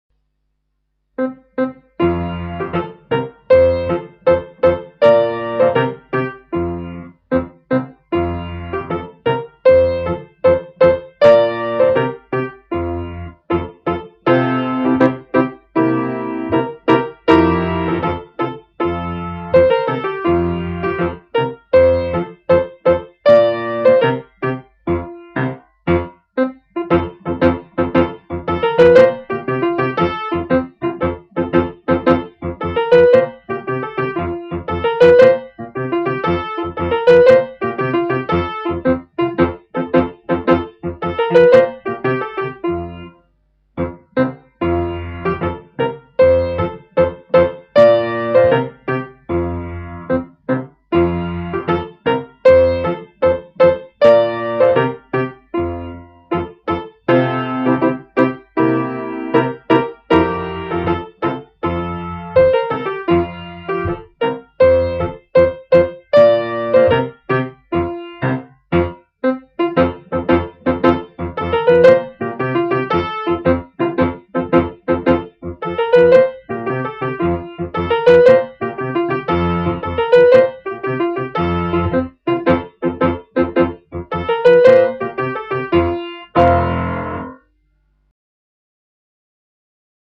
La Monferrina - musique
Description - LA MONFERRINA (chanson et danse traditionnelle de Piémonte)
Monferrina_musica.mp3